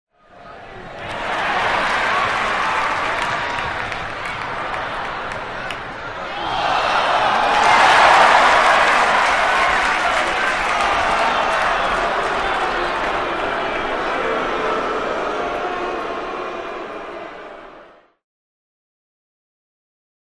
Large Basketball Crowd Big Swell And Boos